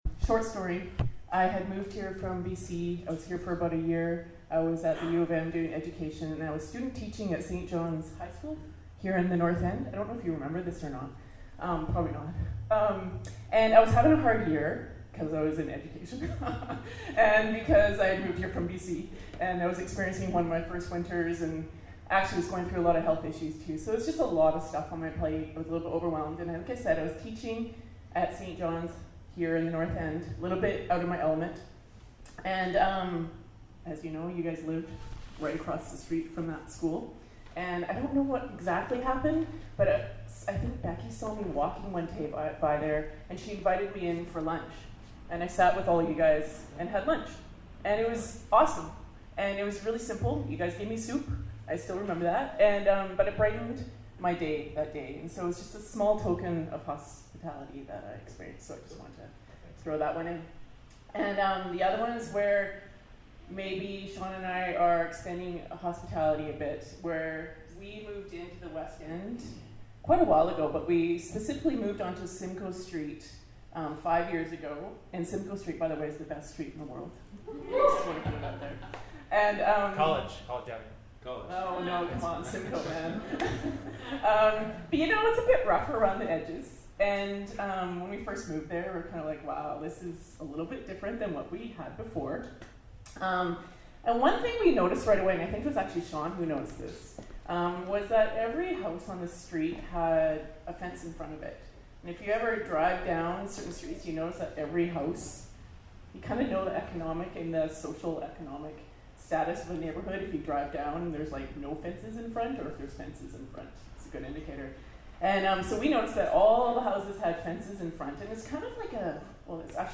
(The recording cuts off a few minutes at the front – apologies)
Service Type: Upstairs Gathering